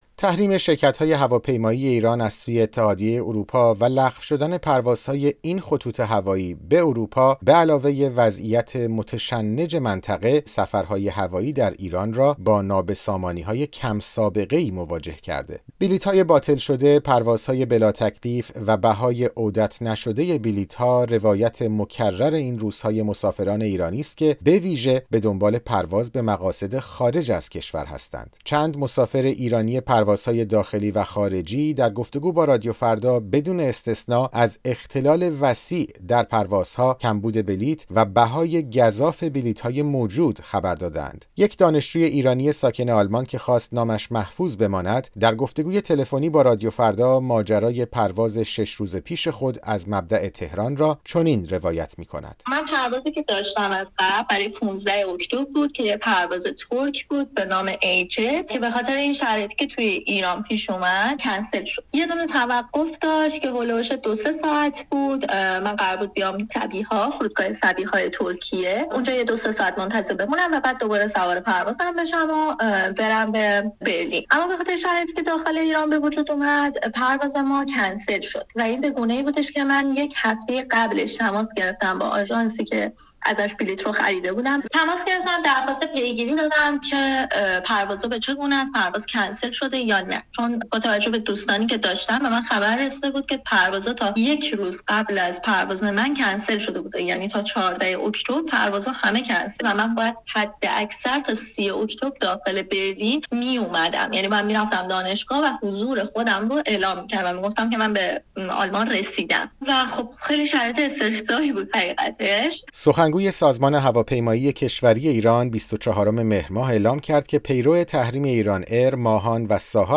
گزارش رادیویی درباره «نابسامانی» در پروازهای مسافربری ایران